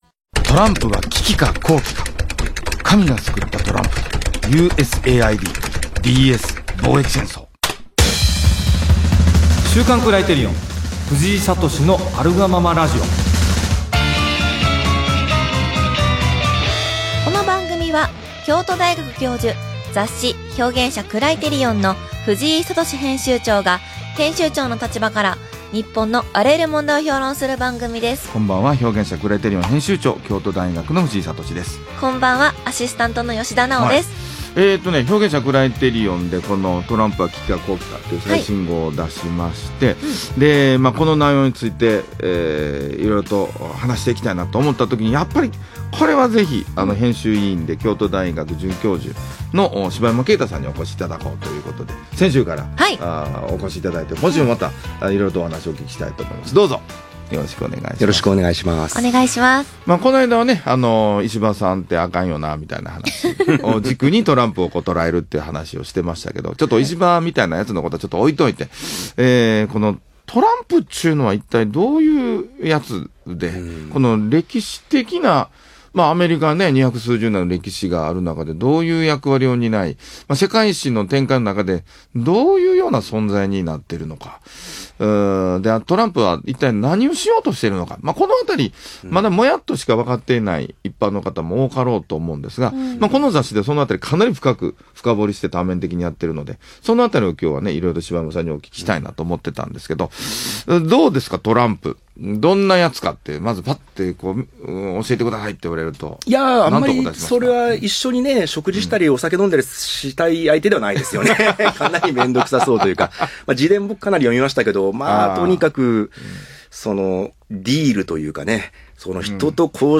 【ラジオ】「トランプは危機か好機か？」